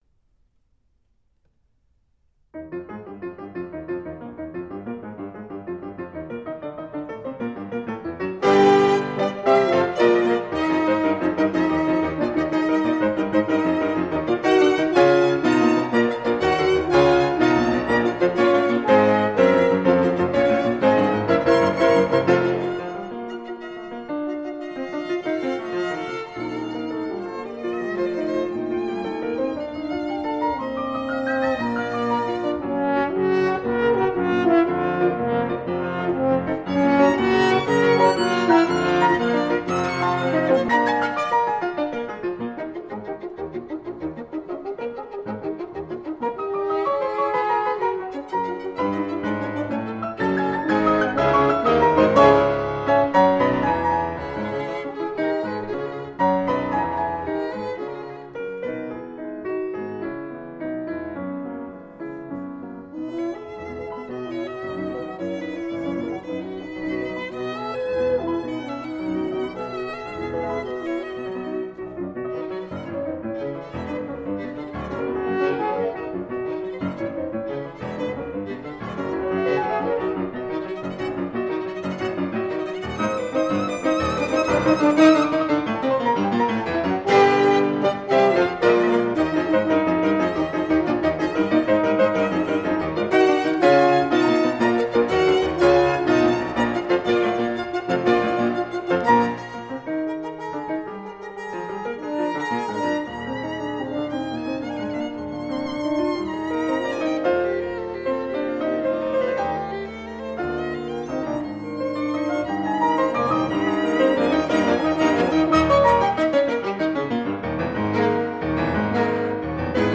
A group of three musicians.
Trio for Piano, Violin and Horn in Eb Major
Scherzo. Allegro